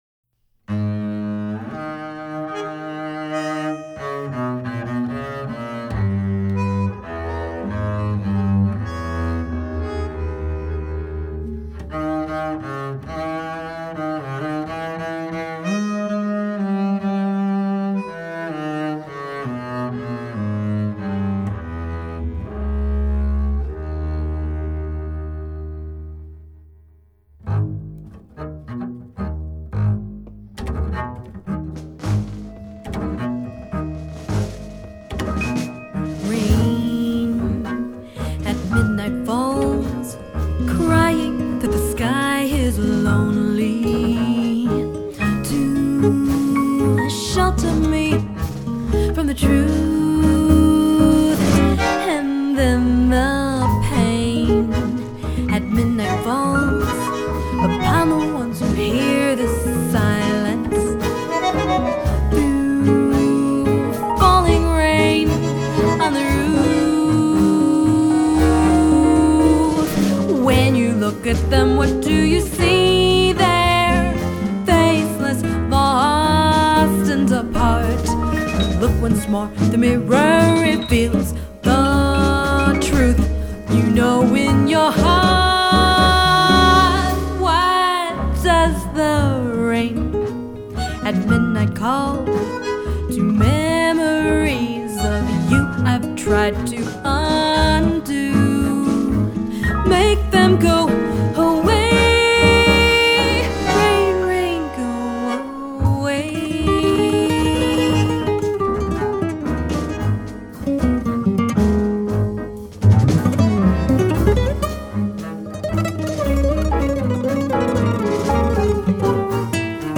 bandoneon